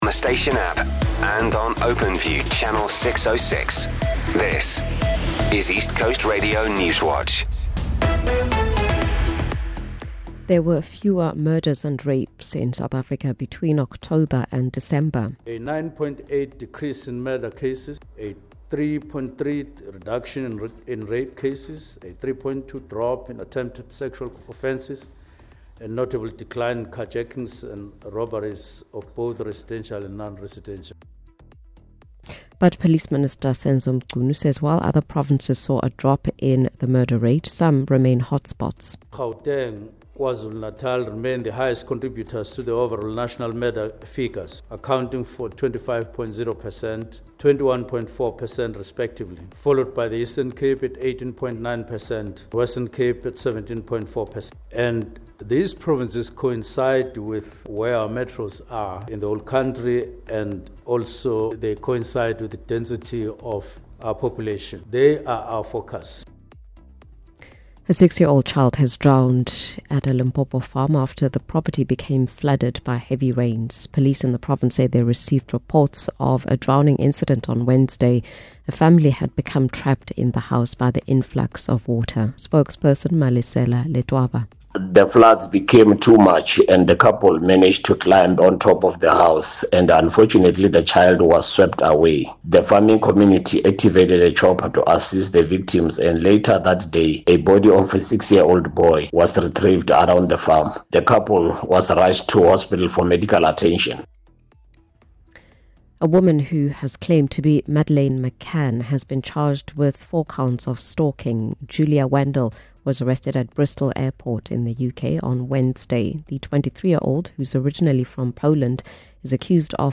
East Coast Radio Newswatch is the independent Durban-based radio station's news team. We are KwaZulu-Natal’s trusted news source with a focus on local, breaking news.